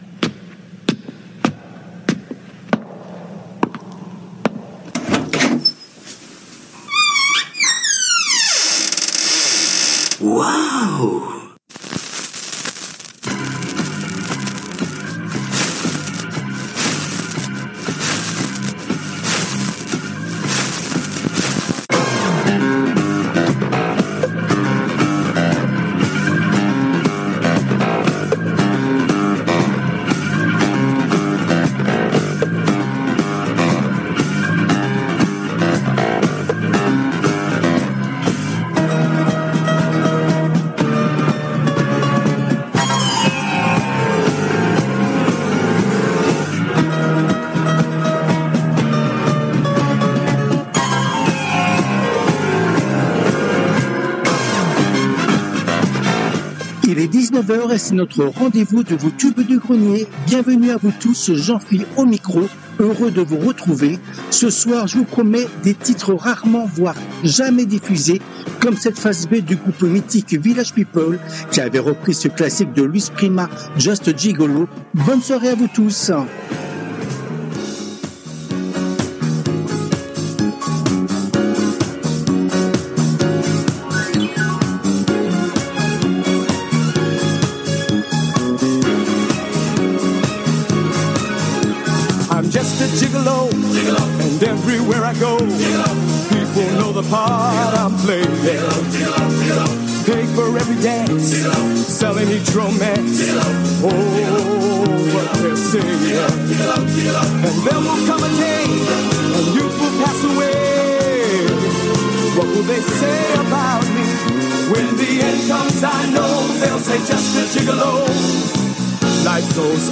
Les Tubes connus ou oubliés des 60's, 70's et 80's